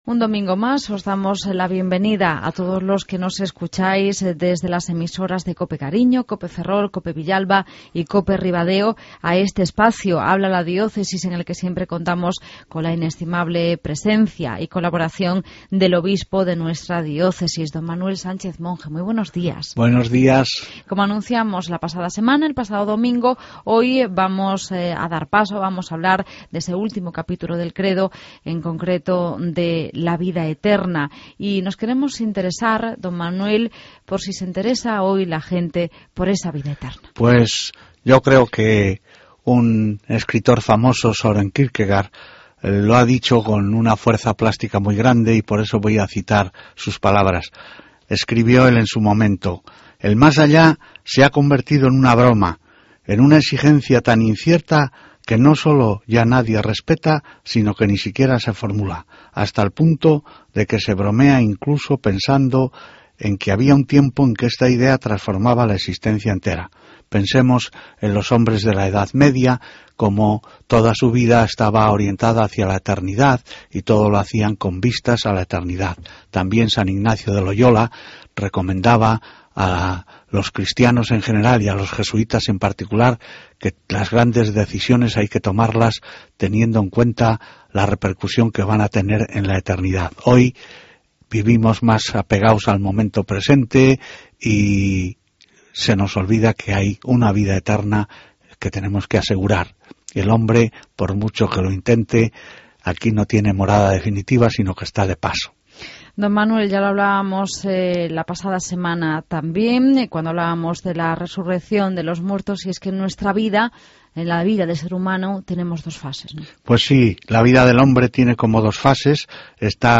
AUDIO: El obispo de nuestra Diócesis, Don Manuel Sánchez Monge, nos habla del último capítulo del Credo: Creo en la vida eterna.